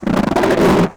ALIEN_Communication_26_mono.wav